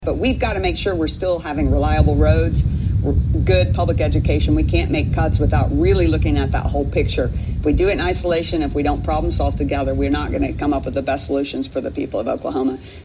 CLICK HERE to listen to commentary from Senator Julia Kirt.